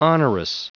Prononciation du mot onerous en anglais (fichier audio)
Prononciation du mot : onerous